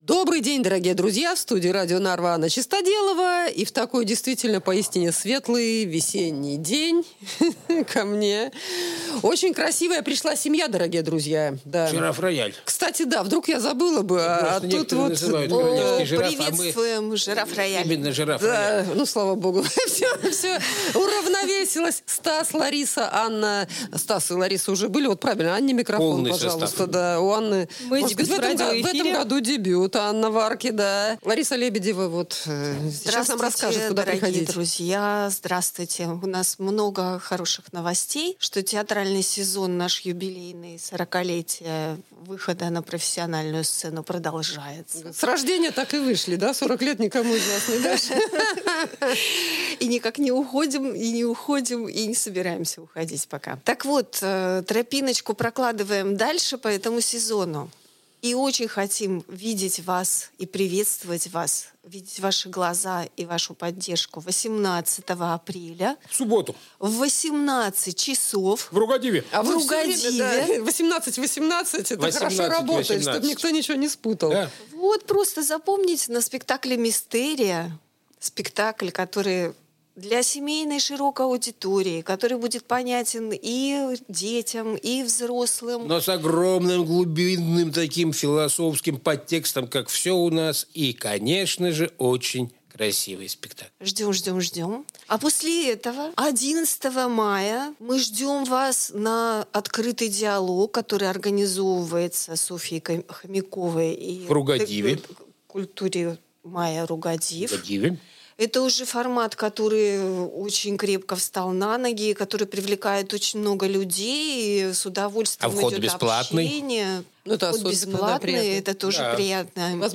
Подробнее — из первых рук: в студии radio Narva